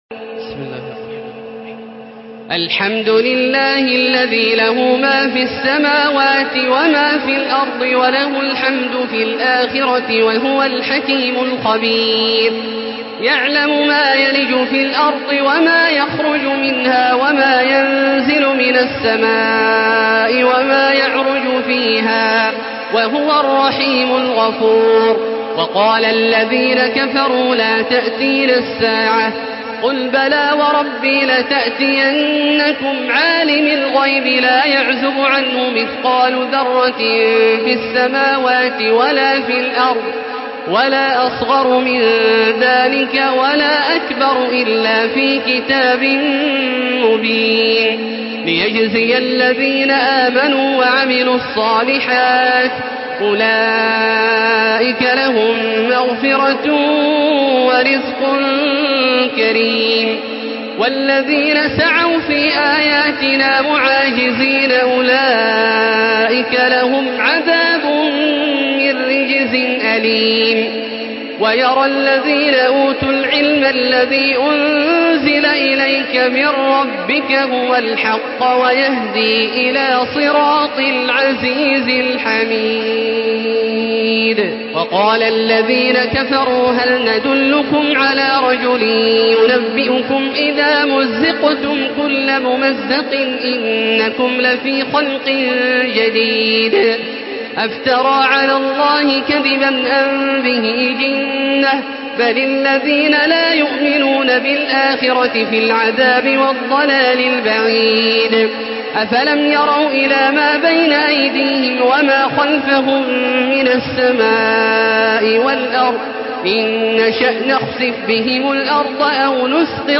تراويح الحرم المكي 1435
مرتل حفص عن عاصم